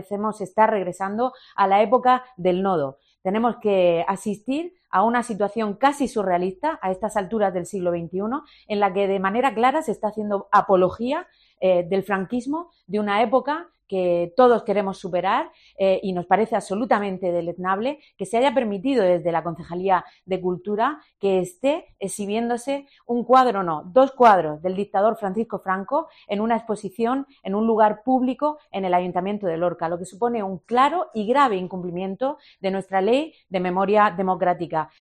Isabel Casalduero, concejal del PSOE en Lorca